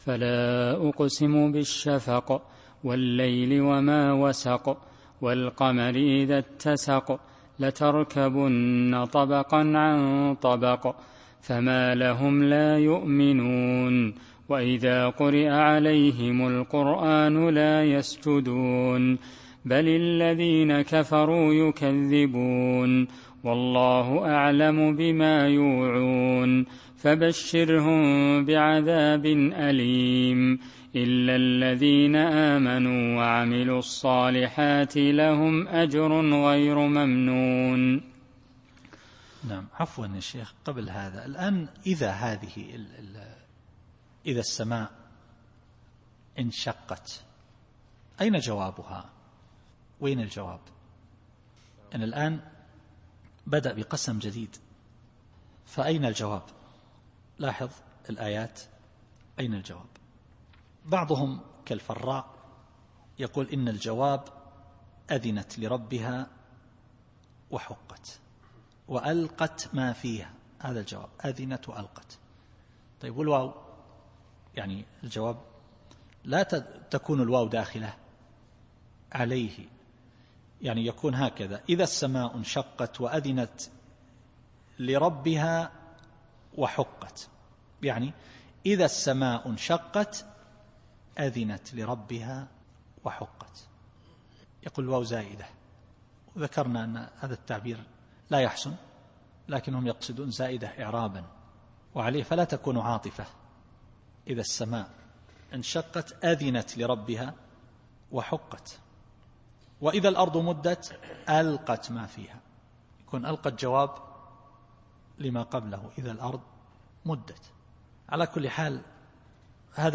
التفسير الصوتي [الانشقاق / 16]